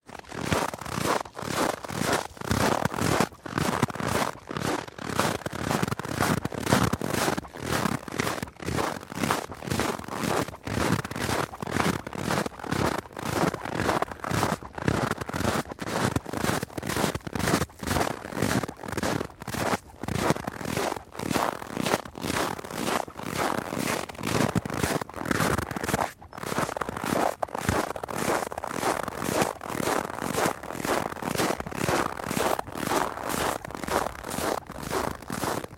winter » footsteps shoes packed snow medium speed mic less close
描述：footsteps shoes packed snow medium speed mic less close.flac
标签： packed speed snow footsteps shoes medium
声道立体声